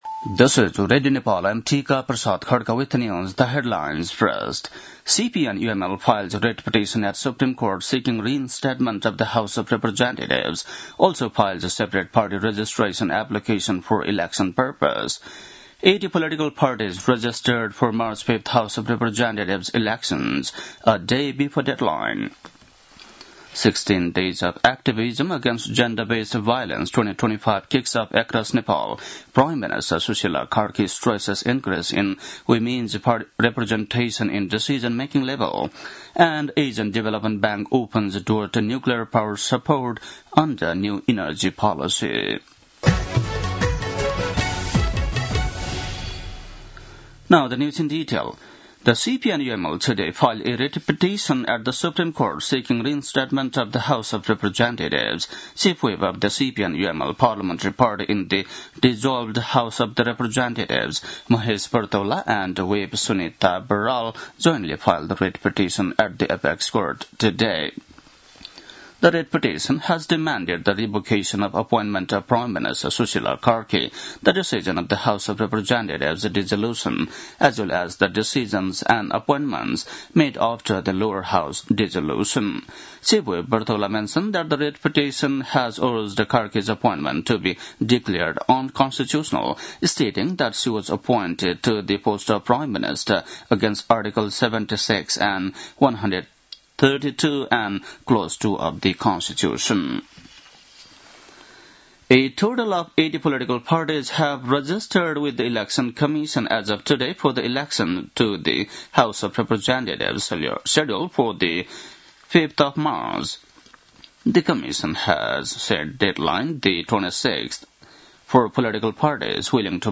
बेलुकी ८ बजेको अङ्ग्रेजी समाचार : ९ मंसिर , २०८२